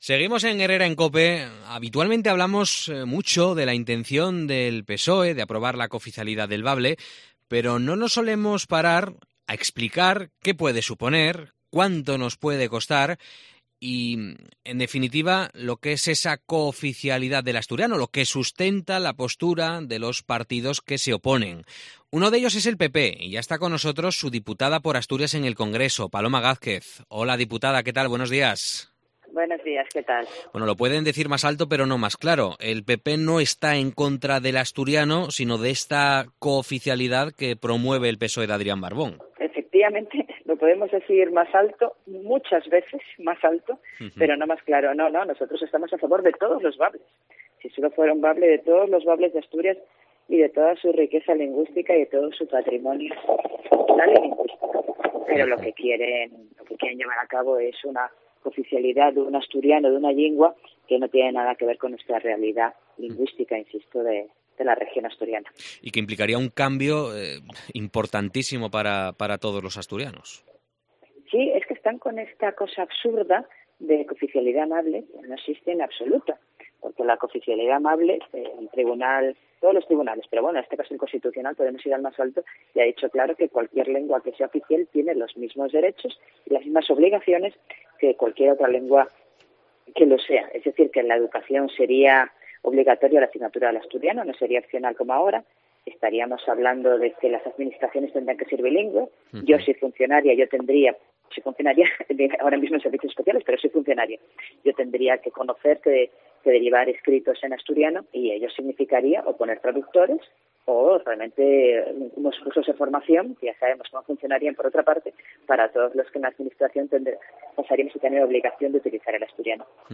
Entrevista a la diputada del PP por Asturias, Paloma Gázquez